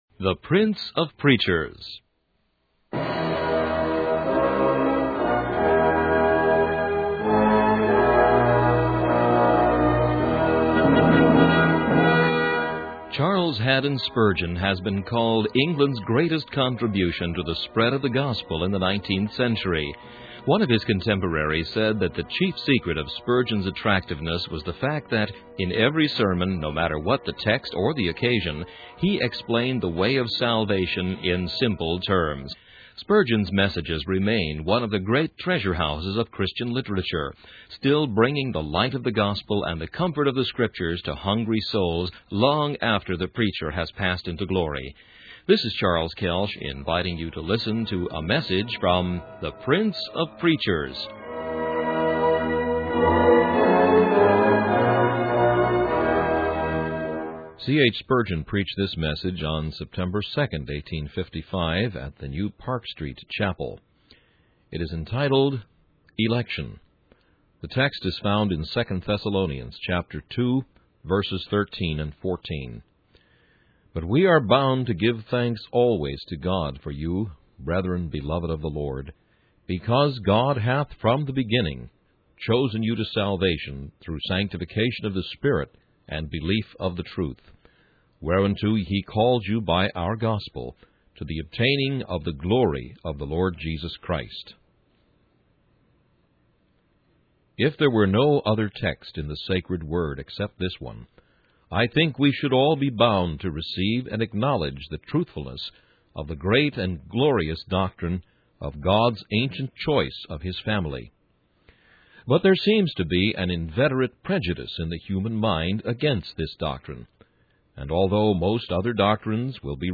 In this sermon, the preacher emphasizes the importance of turning Scripture into practical discourse under the influence of God's Spirit.